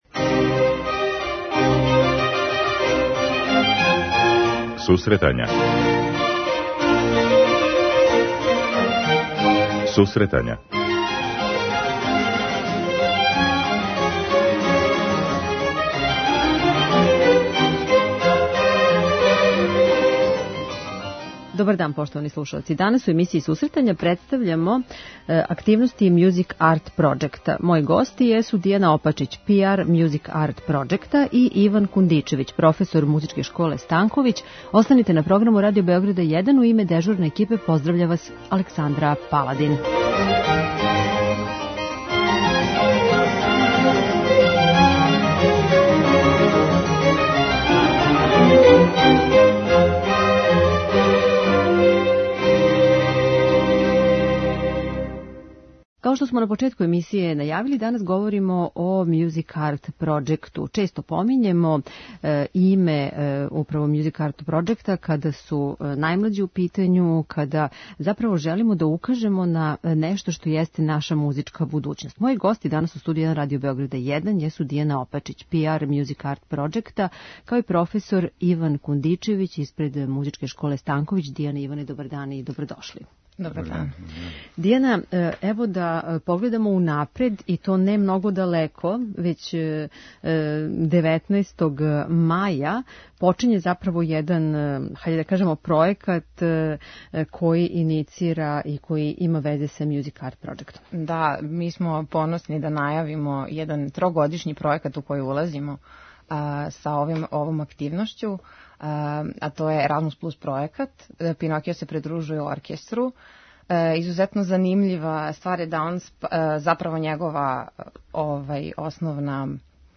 преузми : 10.44 MB Сусретања Autor: Музичка редакција Емисија за оне који воле уметничку музику.